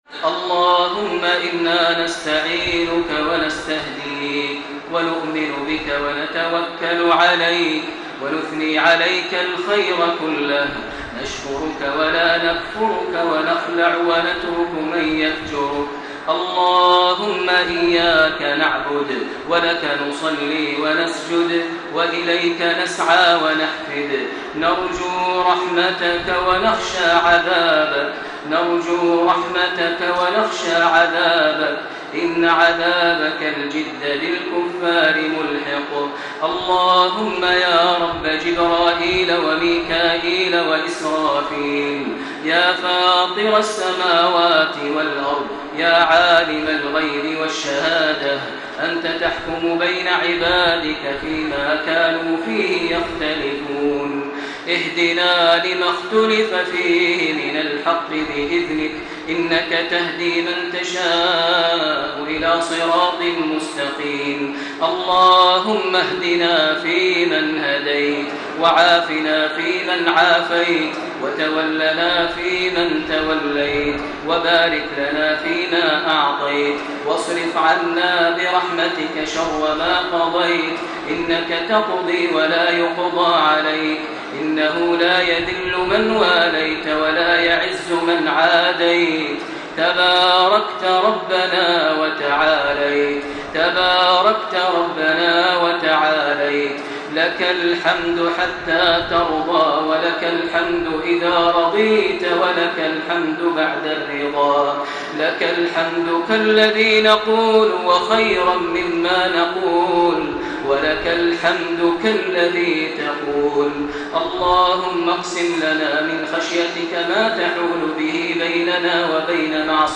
دعاء مؤثر للشيخ ماهر المعيقلي ليلة 4 رمضان 1434هـ.
تسجيل لدعاء خاشع للشيخ ماهر المعيقلي في صلاة التراويح ليلة 4 رمضان 1434هـ.